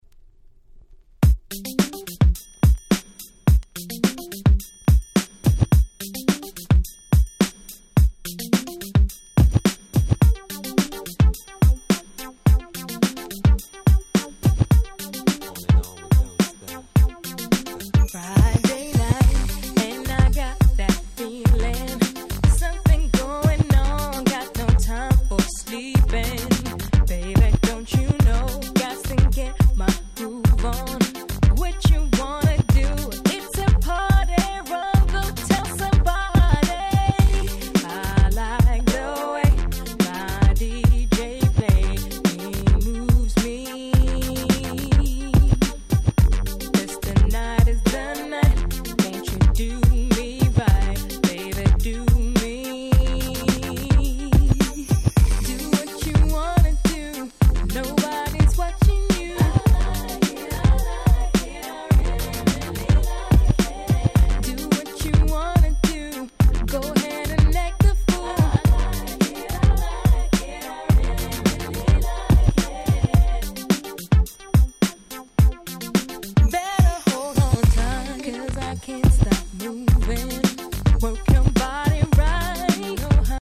99' Nice UK R&B !!
当時のUSメインストリームの流行りを踏襲しつつも、しっかりUKらしさを取り入れている〜と言った所でしょうか？
いぶし銀感満載でなかなか良いです！